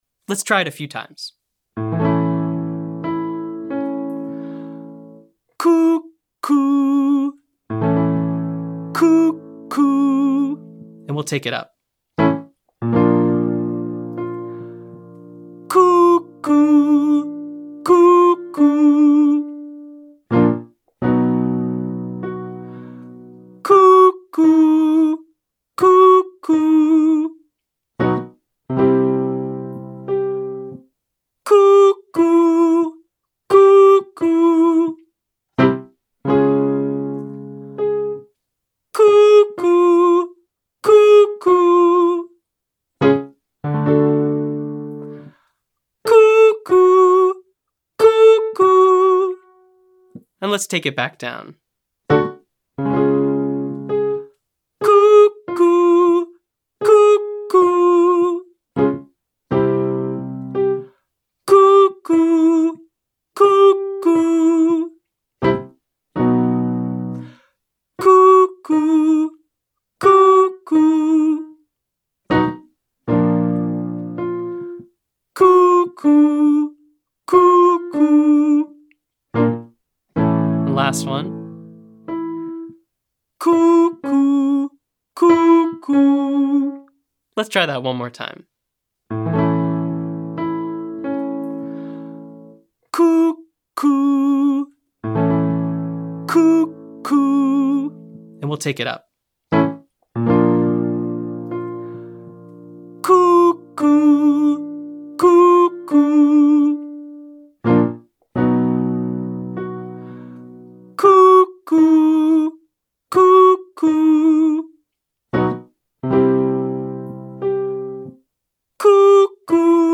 Cord Compression - Online Singing Lesson
• Glottal K Exercise (Cuckoo Clock 53,53) to condition the vocal folds with glottal pressure.
• Sing Freely (1-54321) with an NG sound to feel proper resonance in head voice.